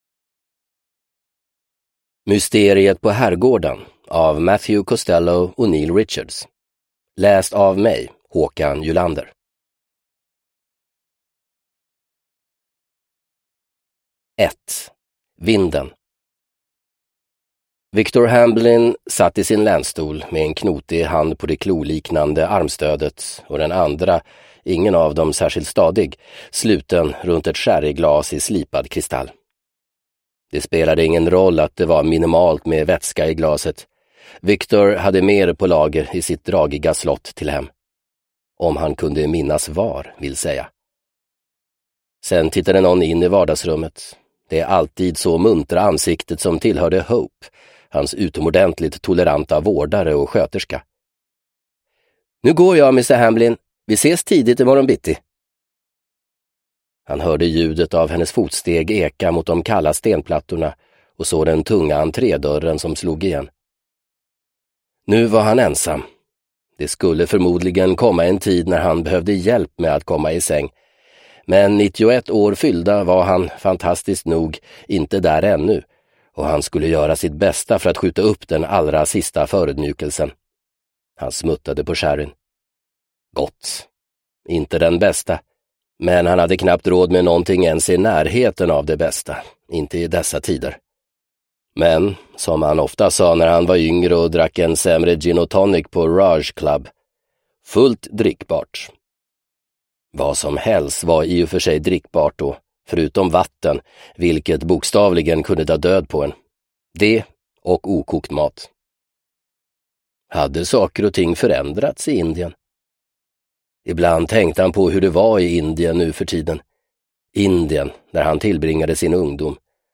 Mysteriet på herrgården – Ljudbok – Laddas ner